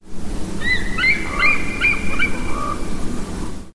Bald Eagle
Bald eagle sound originally from the public domain but I reworked it and improved upon the audio quality. Slight hiss in background.